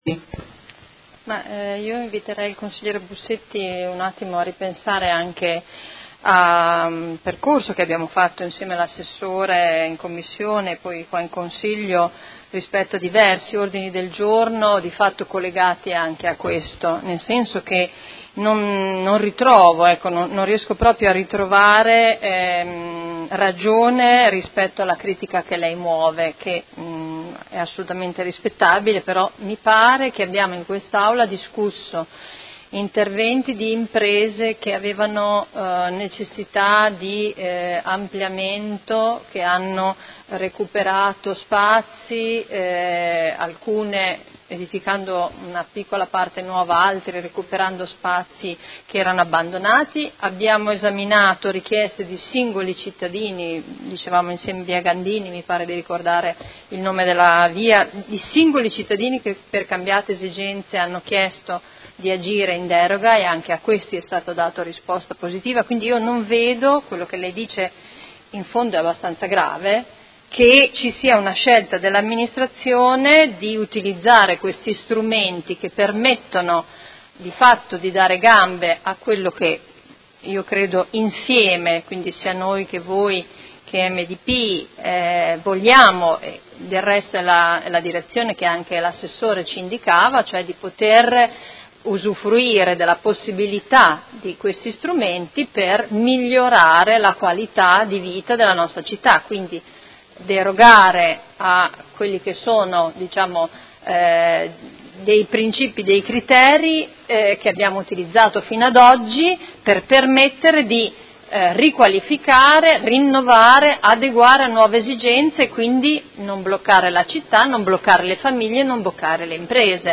Seduta del 19/07/2018 Dibattito.